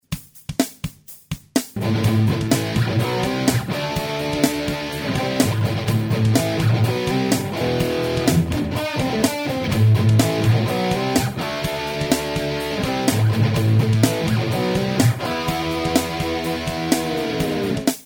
dist.mp3 - с ZOOM'овским исказителем (встроенный комбоэмулятор выключен).
Последний записан в режиме "дабл-трек", так как это повсеместно используемый прием.
Ниже вы послушаете грязно-гранжевский рифф.
Софтовый гитарный комбоэмулятор Acoustic Mirror
am_marshall_2000_l&plexi_r_riff_dist.mp3